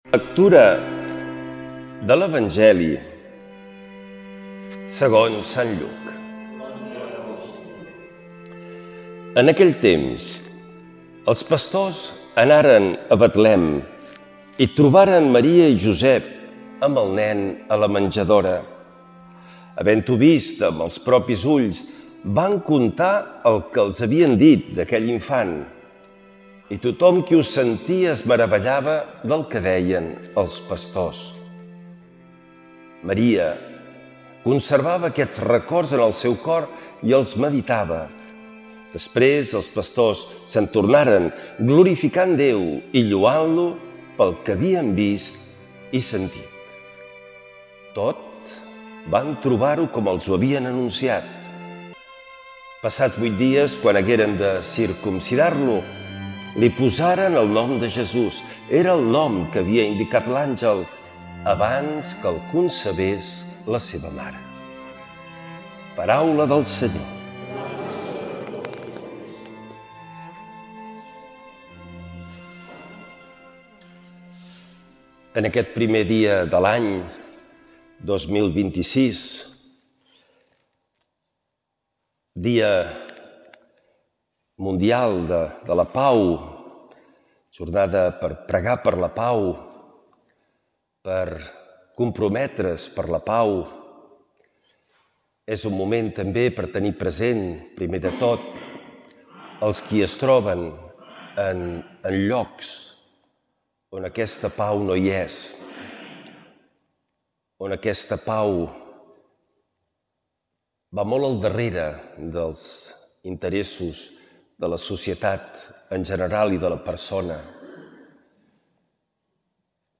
Lectura de l’Evangeli segons Sant Lluc.